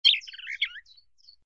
SZ_DG_bird_01.ogg